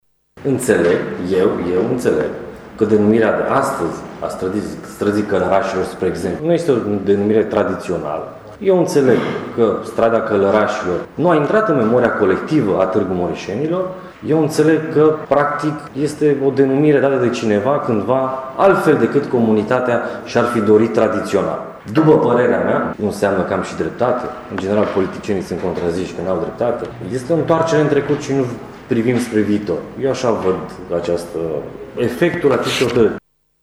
Consilierul local PSD, Olimpiu Sabău Pop, a spus că din punctul său de vedere adoptarea hotărârii este o întoarcere în trecut: